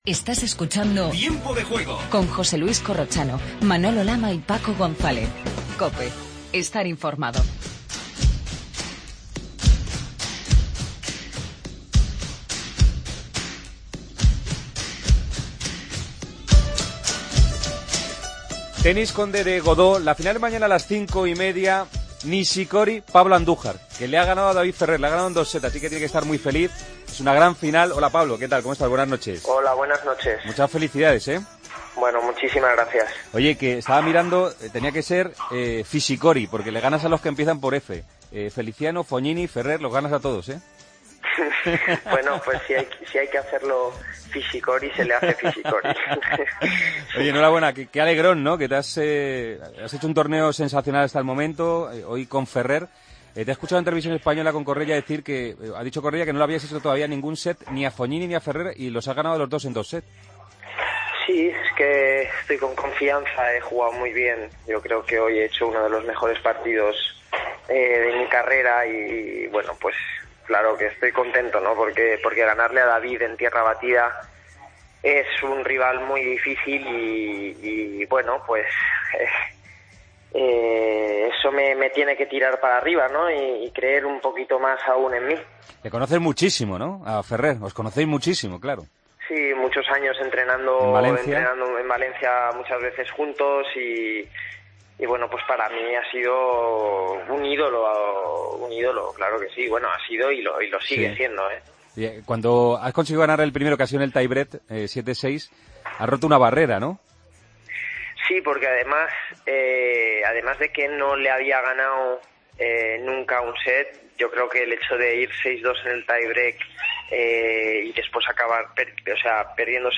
AUDIO: Hablamos con Pablo Andújar, finalista en el Conde de Godó de Tenis.